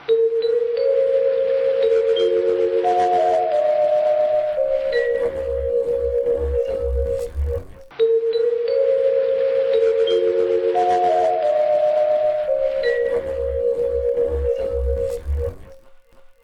Sintonia de la cadena